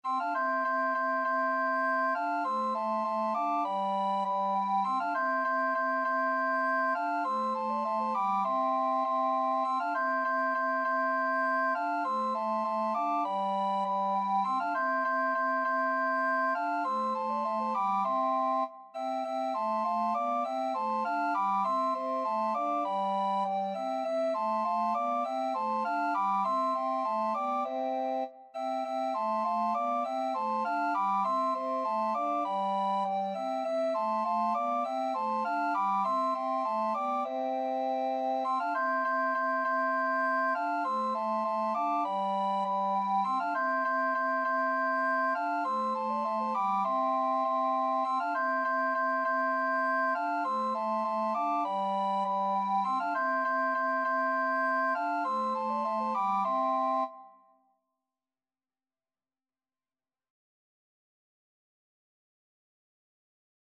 Free Sheet music for Recorder Quartet
Soprano RecorderAlto RecorderTenor RecorderBass Recorder
4/4 (View more 4/4 Music)
C major (Sounding Pitch) (View more C major Music for Recorder Quartet )
Classical (View more Classical Recorder Quartet Music)